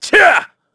Shakmeh-Vox_Attack4_kr.wav